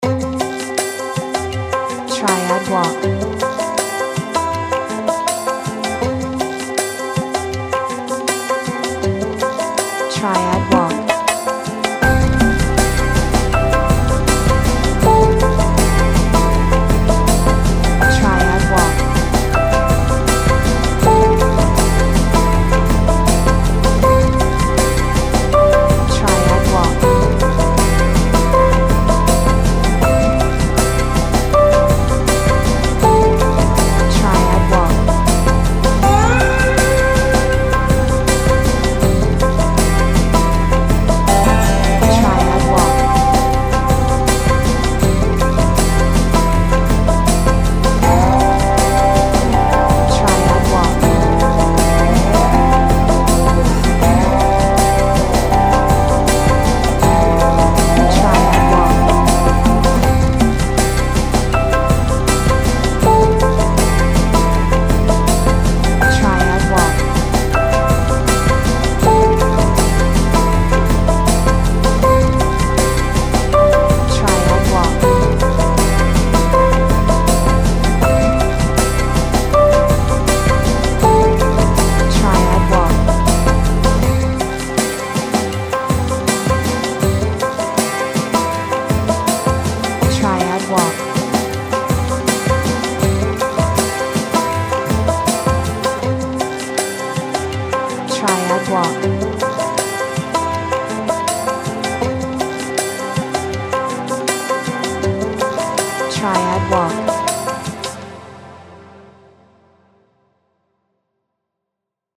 World music , Ambient , Healing